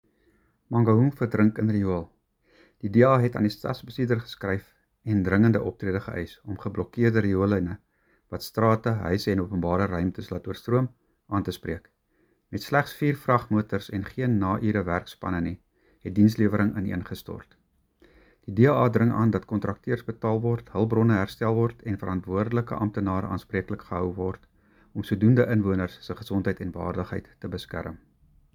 Afrikaans soundbites by Cllr Greg van Noord and